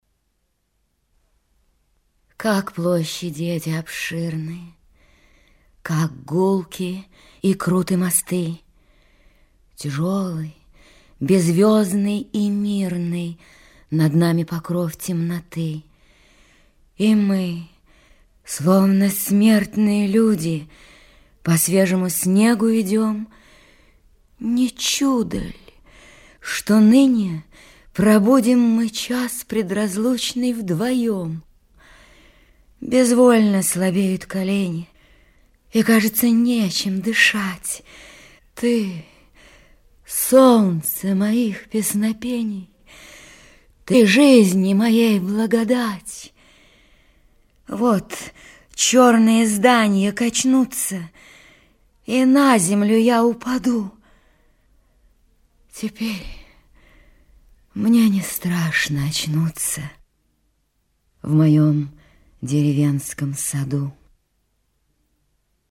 1. «(МБ) Исп. Нина Дробышева – А.Ахматова. Как площади эти обширны…» /